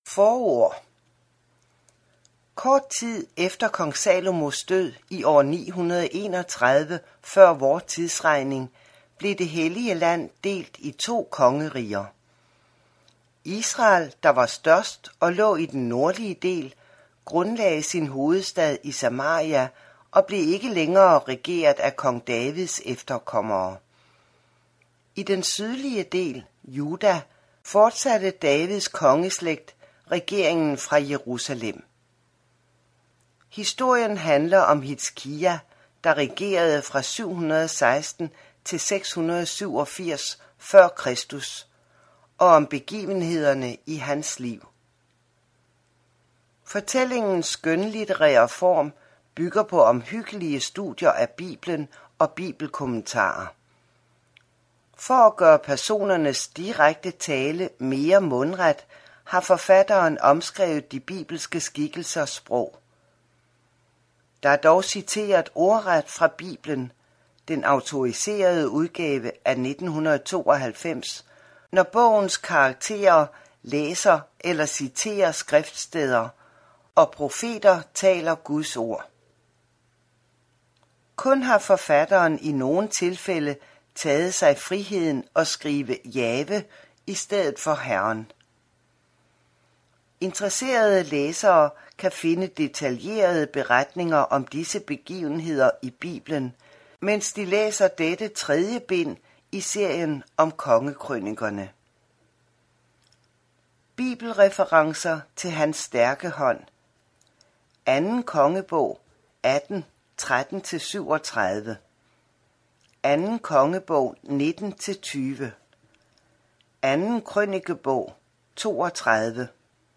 Hør et uddrag af Hans stærke hånd Hans stærke hånd Konge Krøniken III Format MP3 Forfatter Lynn Austin Bog Lydbog E-bog 99,95 kr.